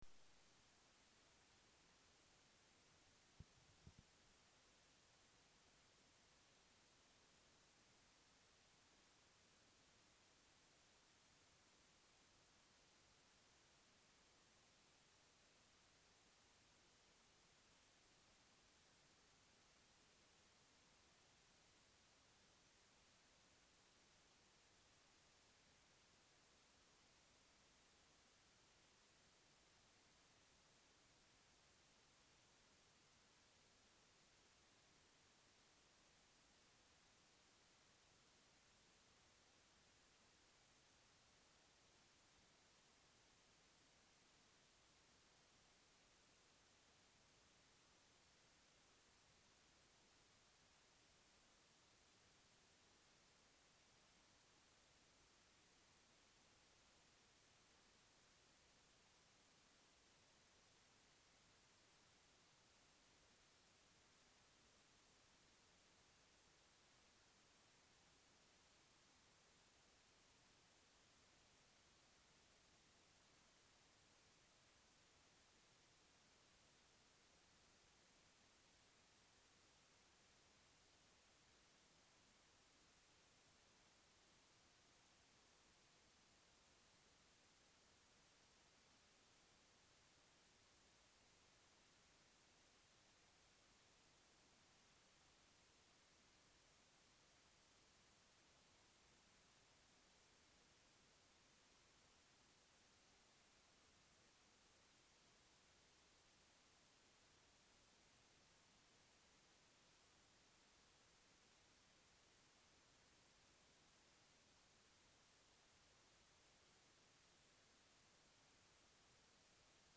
Start of our New Sermon Series on John - St Saviours Church, High Green
1 The Word. Start of our New Sermon Series on John